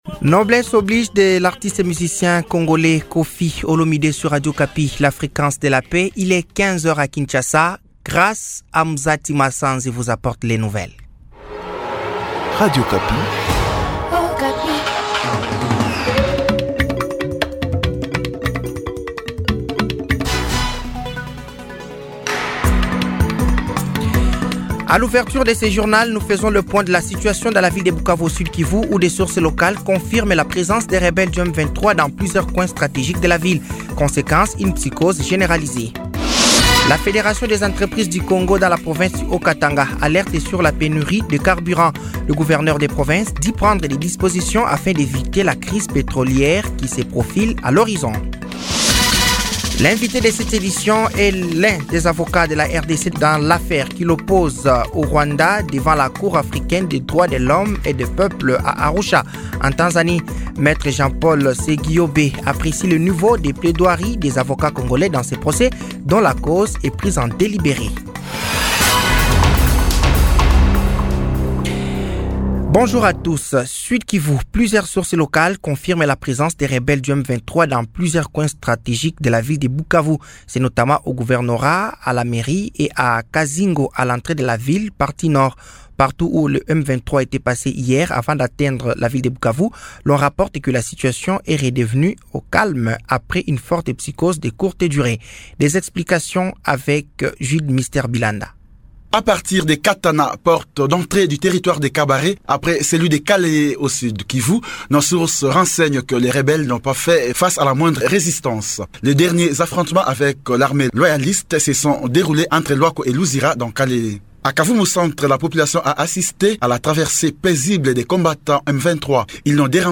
Journal français de 15h de ce samedi 15 février 2025